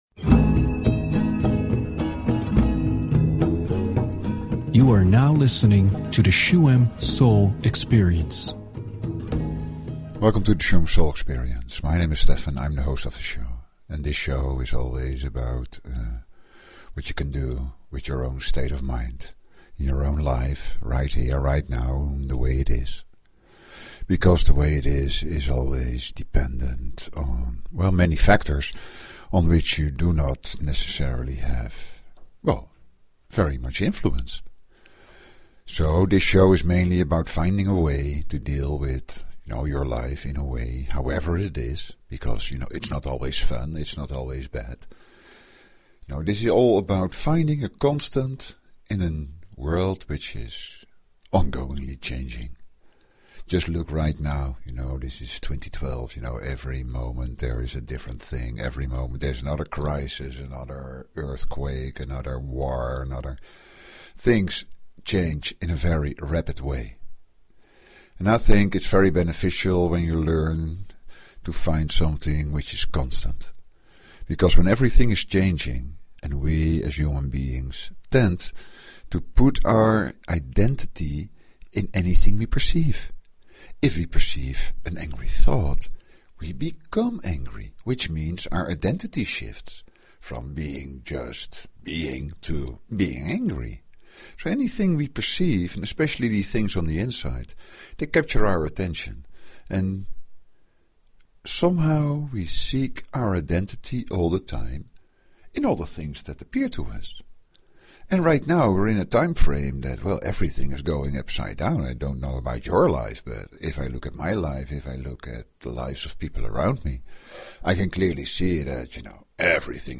Talk Show Episode, Audio Podcast, Shuem_Soul_Experience and Courtesy of BBS Radio on , show guests , about , categorized as
To ease up this shift towards inner peace and inner values, Shuem Soul Experience offers a shamanic meditation ritual in the second half of the show.